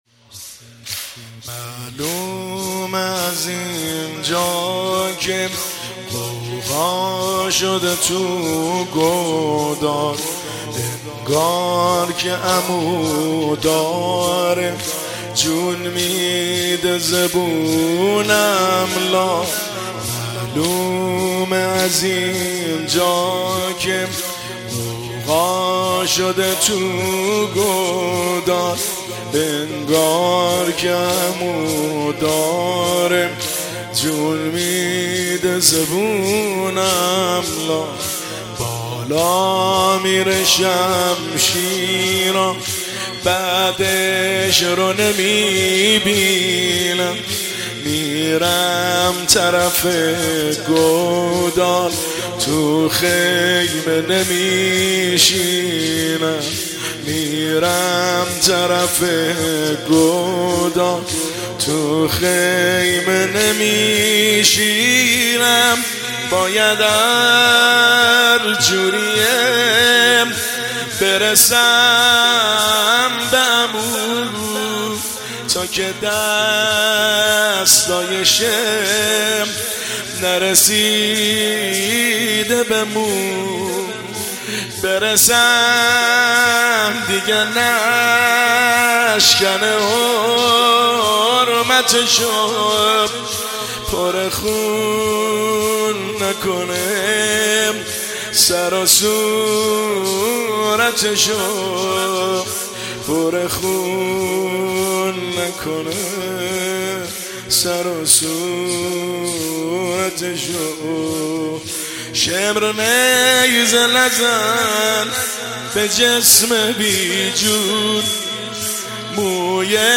زمینه – شب پنجم محرم الحرام 1404